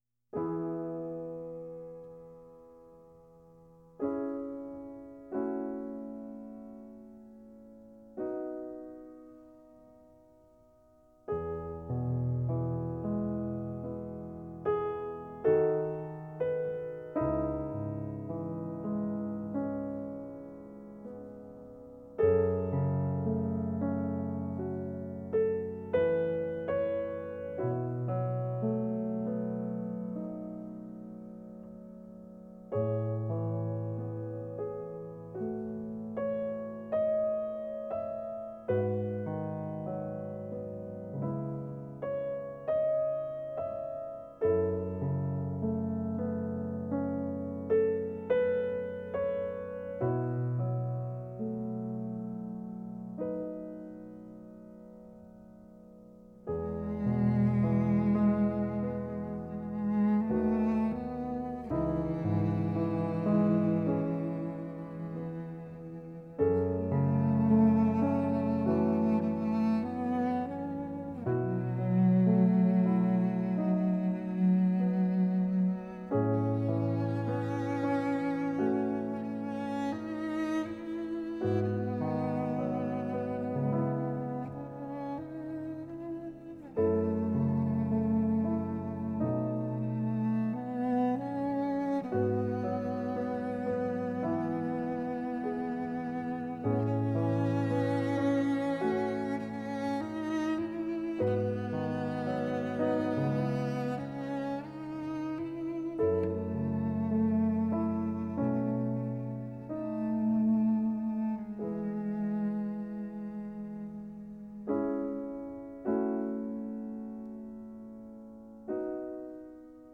Жанр: Score